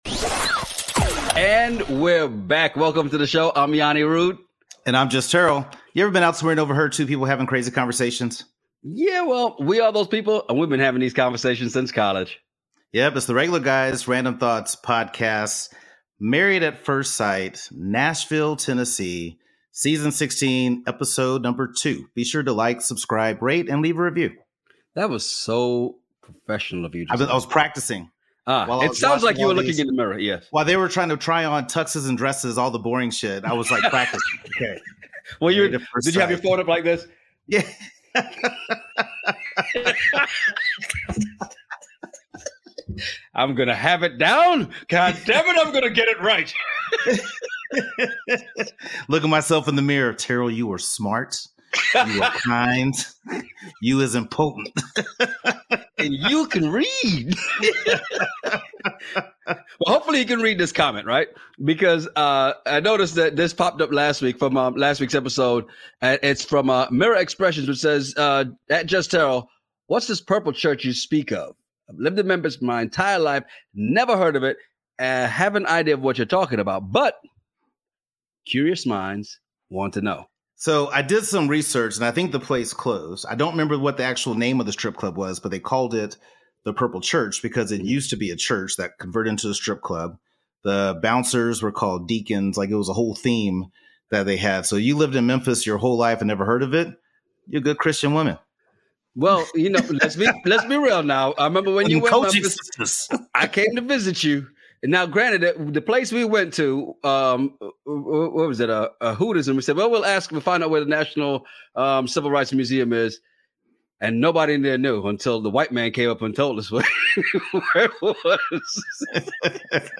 Ever been somewhere and overheard two guys having a crazy conversation over random topics?